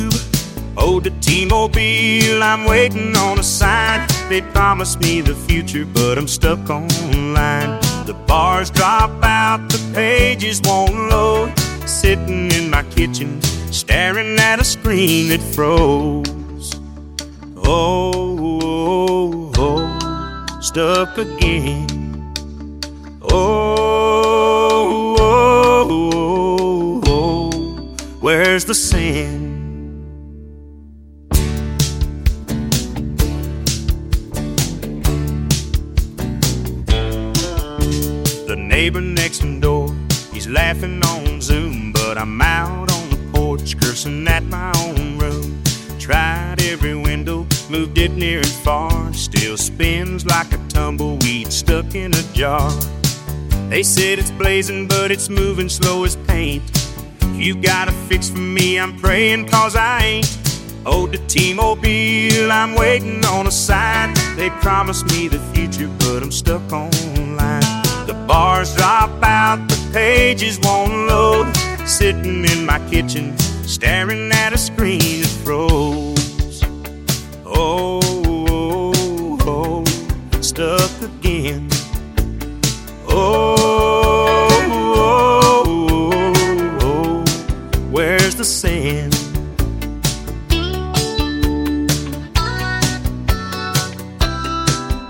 AI music created in the MusicKraze iPhone app
Tags: Country